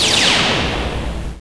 サンプリングしたものや他の方から提供されたものではない，全てシンセで新規作成したデータですので，著作権の心配なく自由に使用できます。
（効果音作成に使用したシンセ。　YAMAHA V50,TG-500,SY99。その他エフェクター類）
チュチュン！！